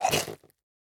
Minecraft Version Minecraft Version snapshot Latest Release | Latest Snapshot snapshot / assets / minecraft / sounds / mob / strider / eat3.ogg Compare With Compare With Latest Release | Latest Snapshot
eat3.ogg